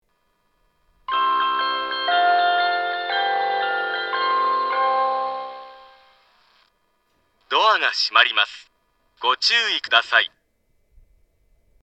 スピーカーはすべてユニペックスマリンです。
発車メロディー
一度扱えばフルコーラス鳴ります。
スピーカーがユニペックスマリンなので音質が悪いです。